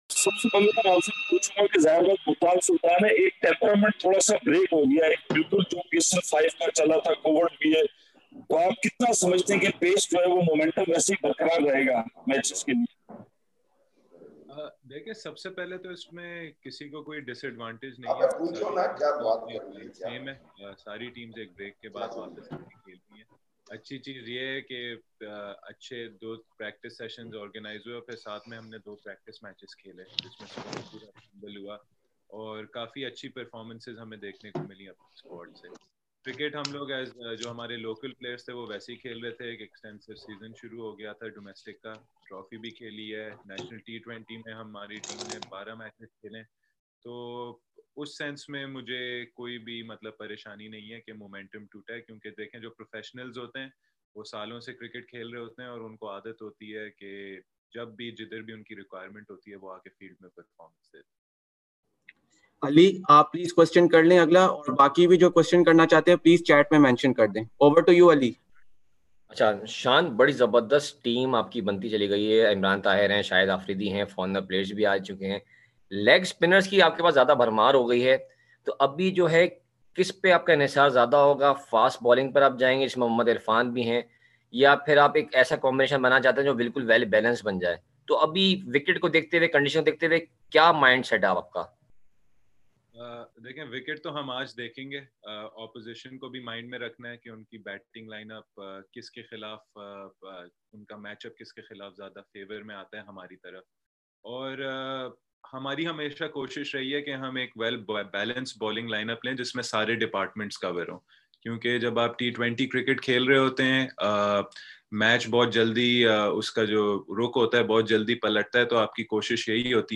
Multan Sultans captain Shan Masood and Karachi Kings captain Imad Wasim held pre-match virtual media conferences with the local media today